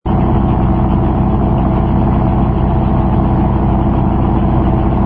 engine_rh_fighter_loop.wav